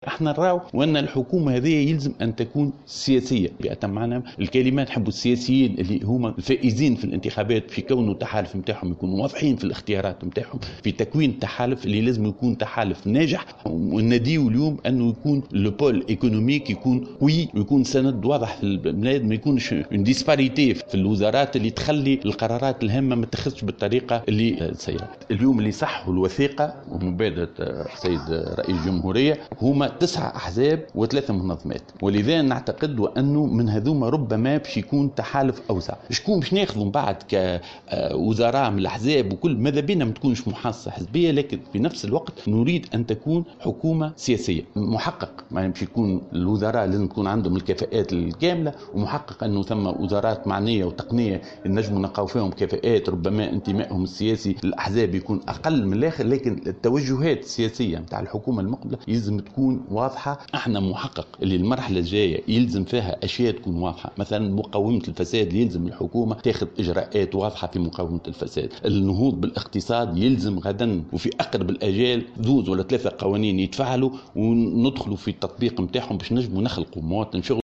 وأكد في تصريحات صحفية على اثر اجتماع للمجلس الوطني لحزب آفاق تونس المنعقد اليوم الأحد أهمية أن تتصف حكومة الوحدة الوطنية ب"النجاعة وأن تعمل بأفكار واضحة وقابلة للتنفيذ والإنجاز".